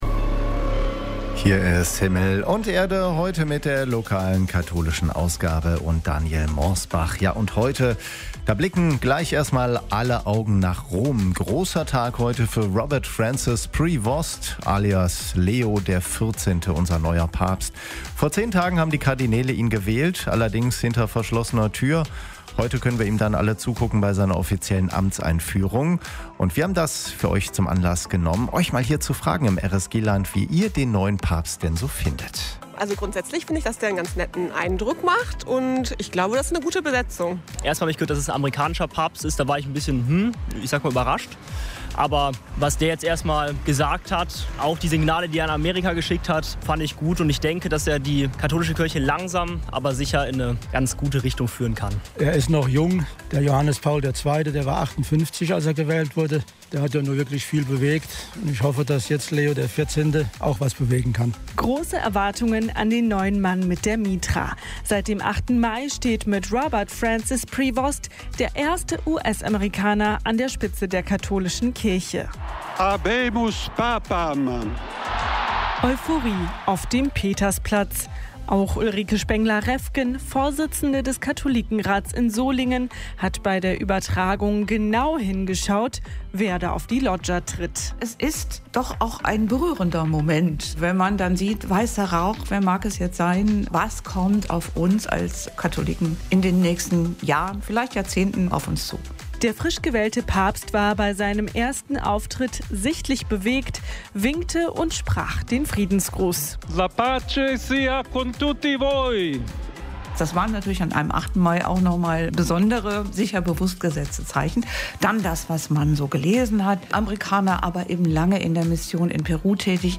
Papstwahl in Rom – Stimmen und Eindrücke aus dem RSG-Land
Großer Tag für die katholische Kirche: Mit Leo dem 14. wird heute der erste US-Amerikaner offiziell ins Papstamt eingeführt. Wir haben die feierliche Amtseinführung zum Anlass genommen, im RSG-Land nach euren Eindrücken zum neuen Papst zu fragen.